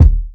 KICK_TAKE_U_FOR_A_RIDE.wav